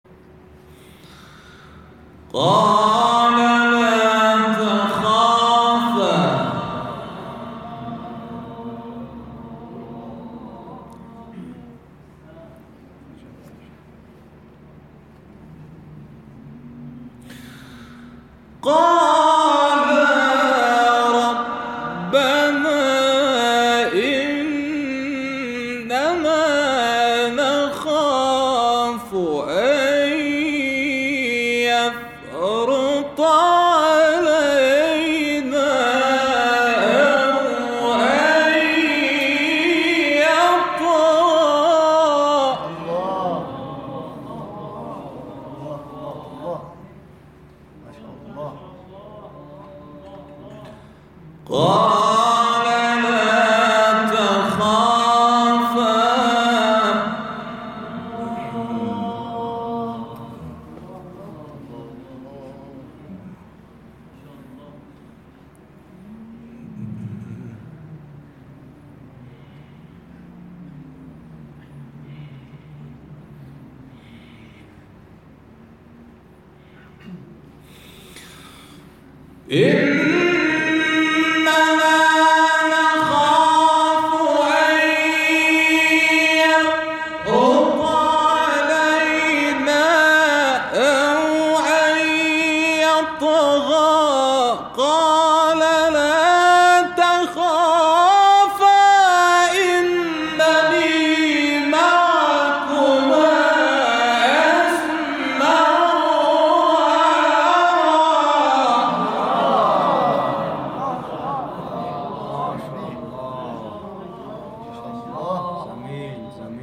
شبکه اجتماعی: مقاطعی صوتی از تلاوت قاریان ممتاز کشور را می‌شنوید.